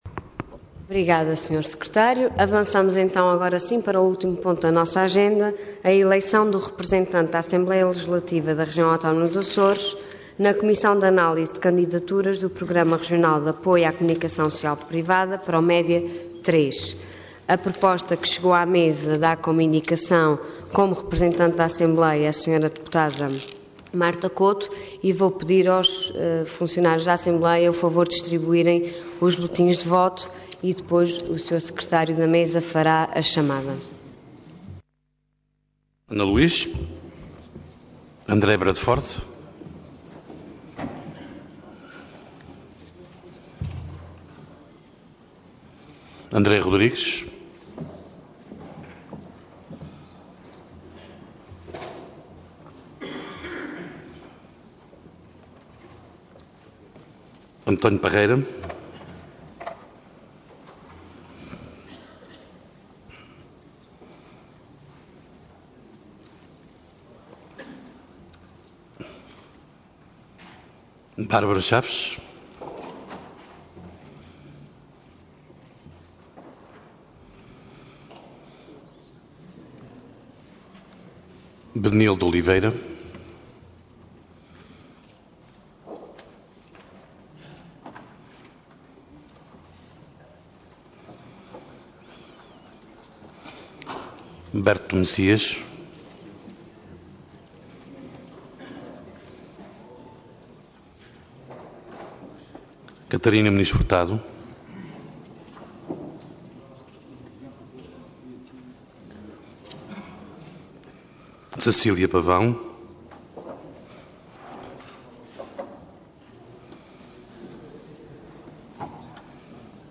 Intervenção Orador Ana Luísa Luís Cargo Presidente da Assembleia Regional